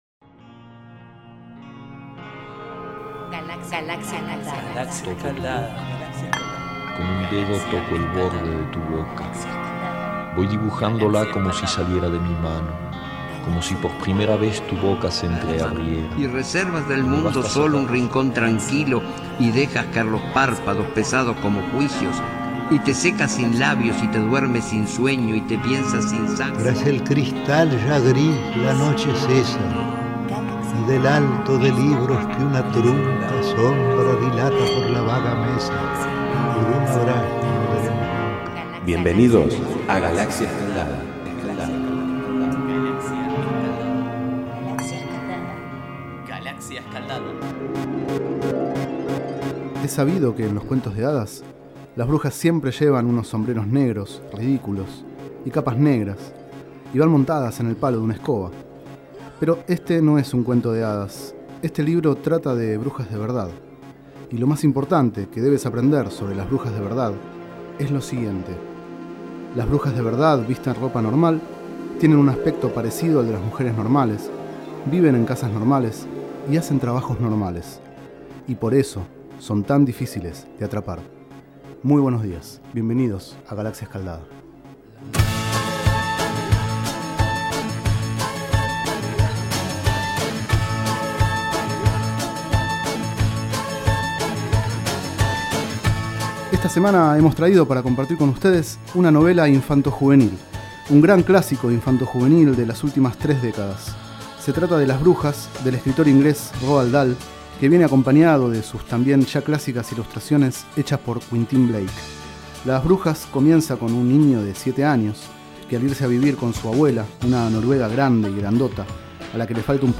30º micro radial, realizado el 06 de octubre de 2012, sobre el libro Las brujas, de Roald Dahl.
Este es el 30º micro radial, emitido en los programas Enredados, de la Red de Cultura de Boedo, y En Ayunas, el mañanero de Boedo, por FMBoedo, realizado el 06 de octubre de 2012, sobre el libro Las brujas, de Roald Dahl.